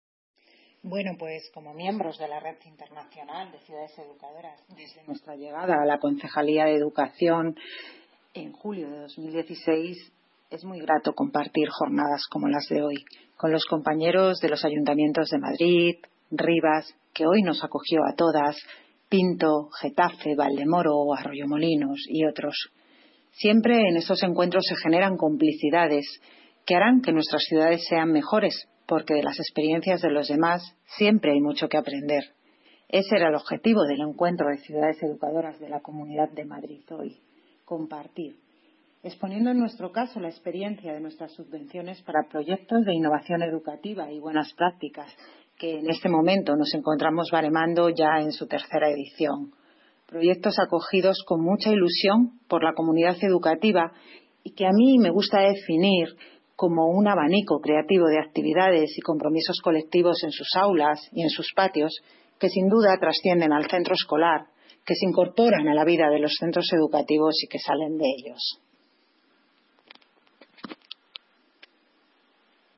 Audio - Isabel Cruceta (Concejala de Educación)